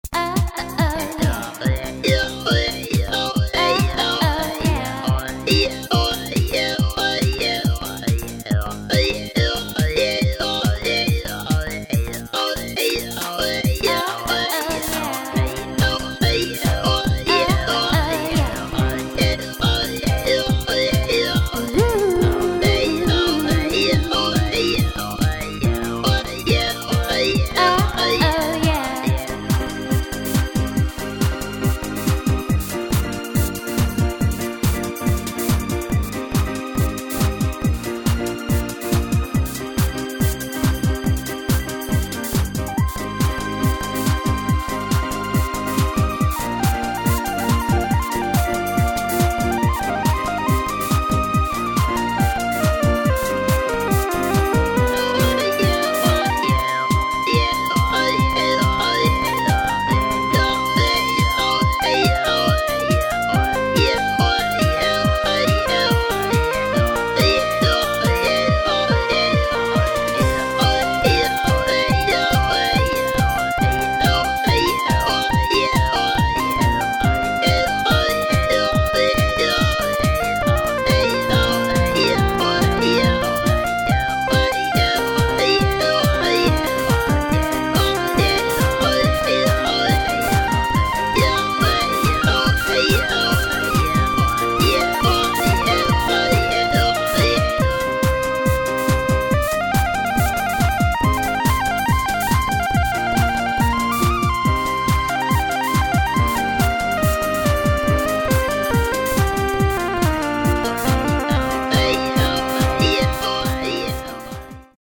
Adult Contemporary Originals